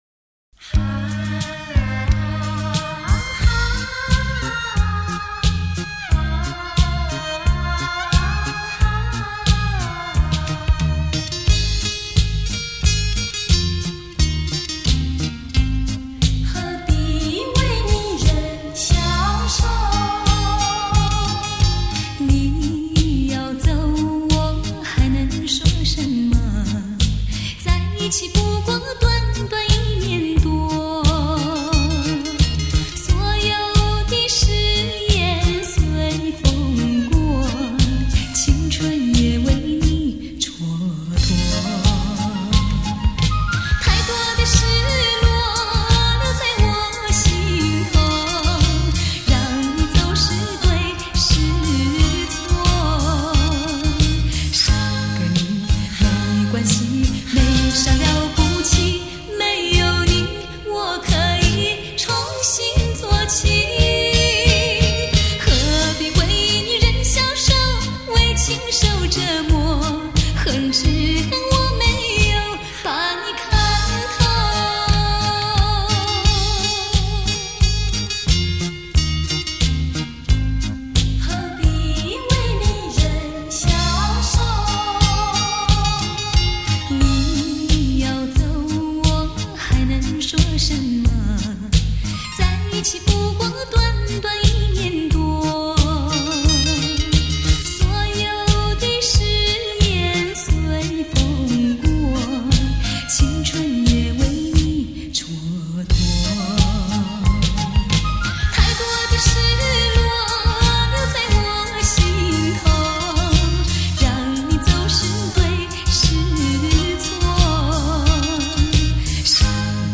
珍藏极品 典范著作 一代甜歌巨星经典金曲